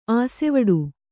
♪ āsevaḍu